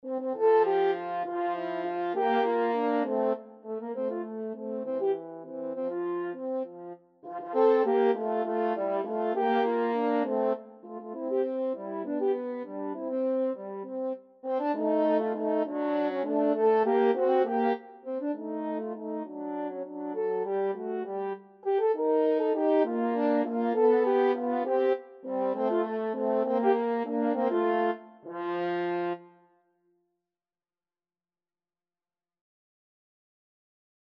3/8 (View more 3/8 Music)
Classical (View more Classical French Horn Duet Music)